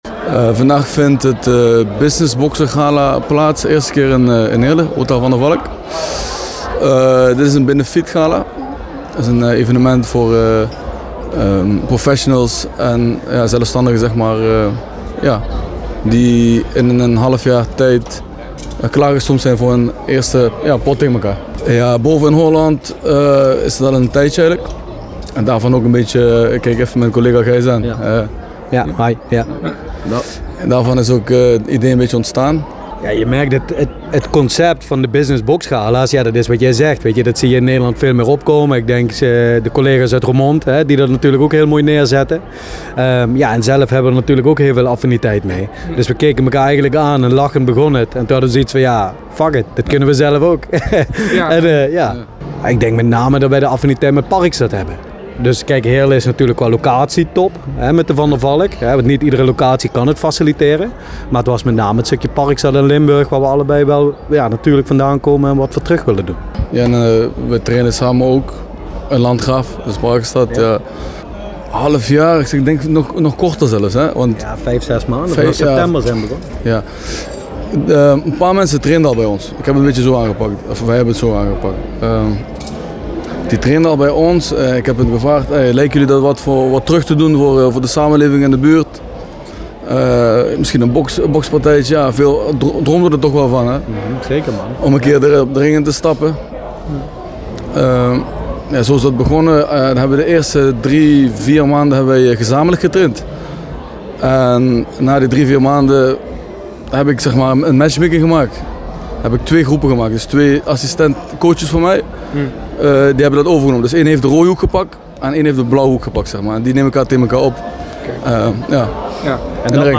Het gehele interview is hieronder te vinden.